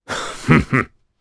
Kaulah-Vox_Happy1_jp.wav